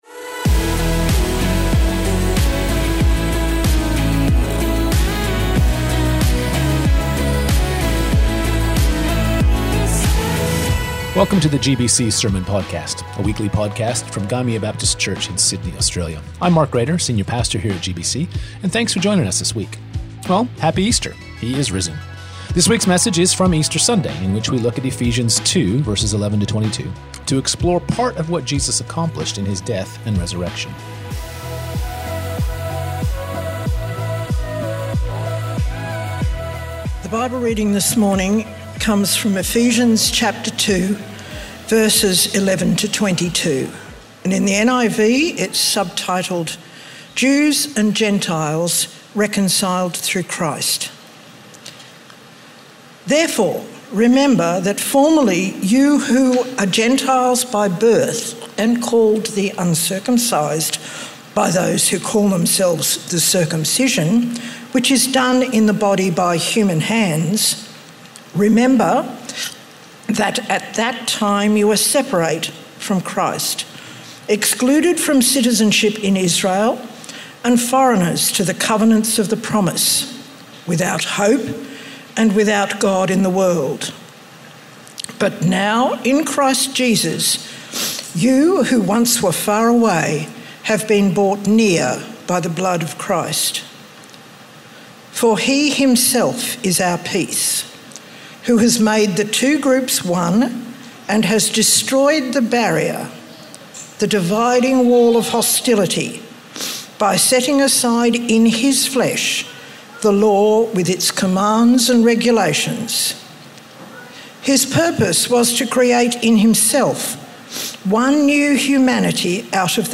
GBC | Sermons | Gymea Baptist Church
This week's message is from Easter Sunday in which we look at Ephesians 2:11-22 to explore part of what Jesus accomplished in his death and resurrection.